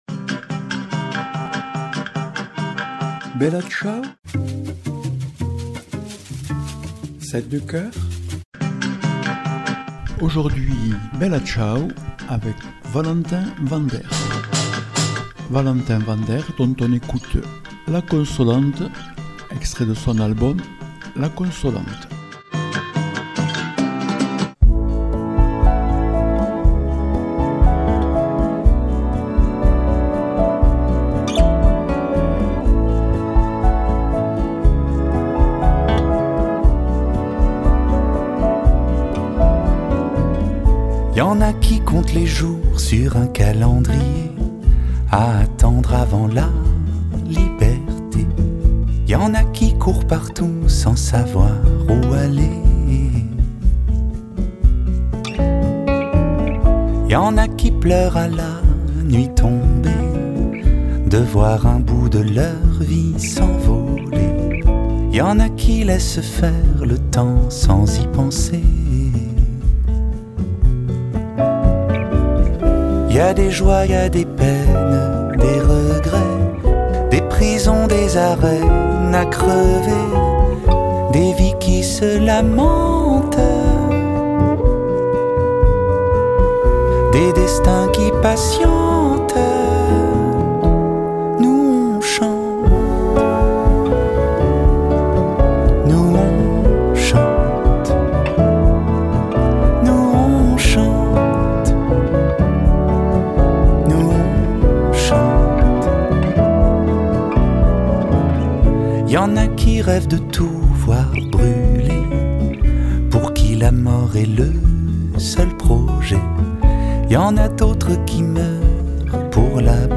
Émissions
auteur-compositeur-musicien.